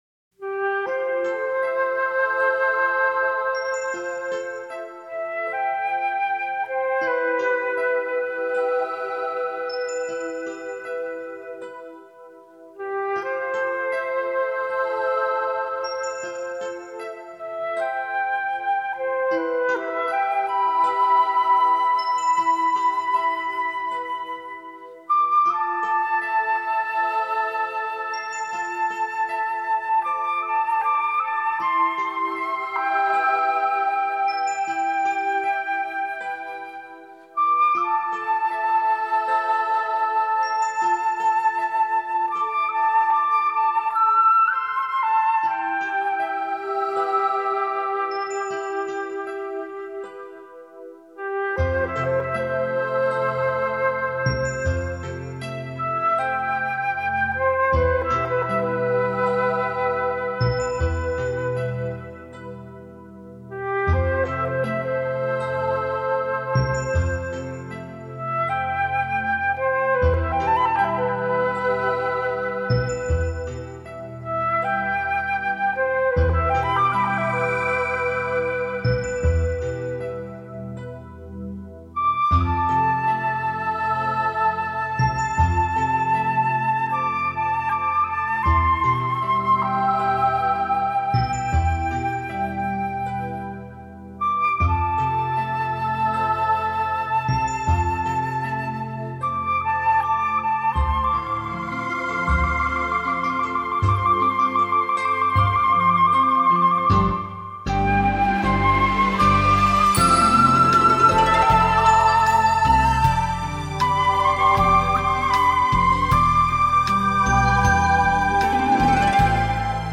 长笛演奏